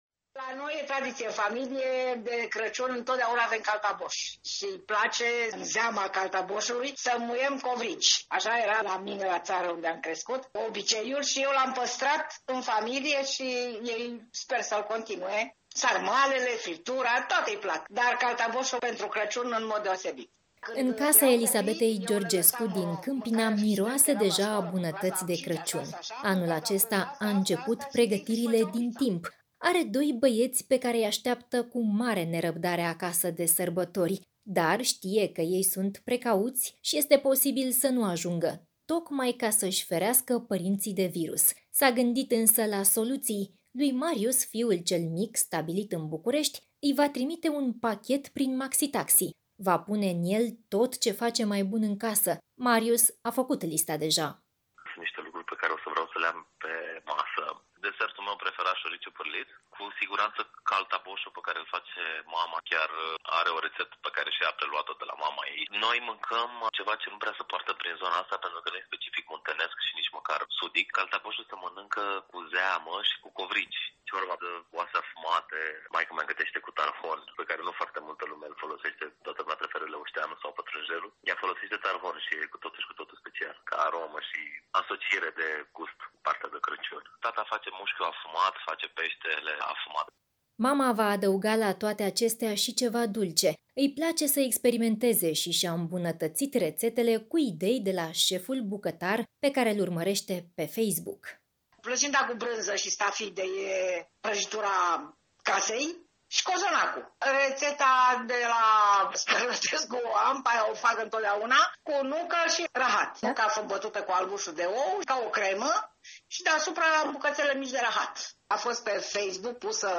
Lumea Europa FM: Cum va arăta masa de Crăciun pe timp de pandemie | REPORTAJ